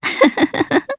Valance-Vox_Happy3_jp_b.wav